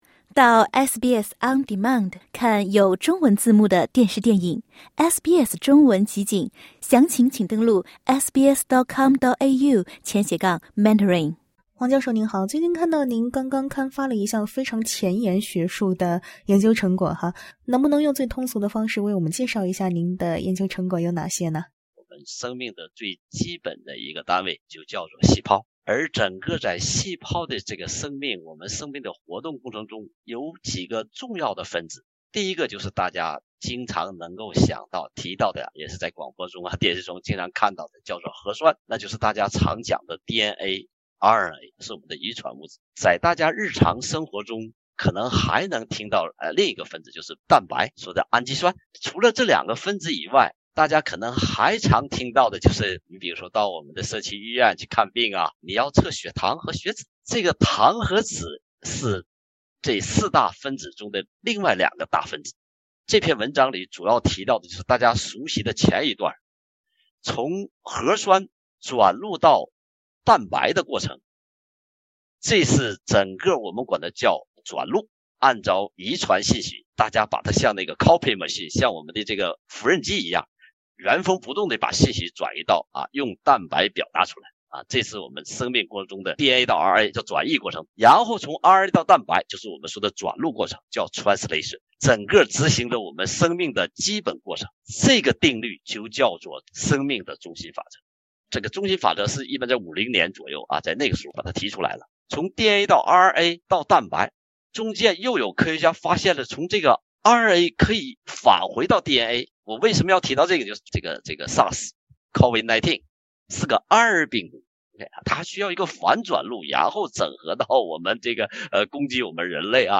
請聽寀訪： LISTEN TO 为何COVID疫苗很快面世，艾滋却没有？